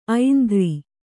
♪ aindri